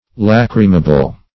Search Result for " lachrymable" : The Collaborative International Dictionary of English v.0.48: Lachrymable \Lach"ry*ma*ble\, a. [L. lacrimabilis, fr. lacrima a tear.]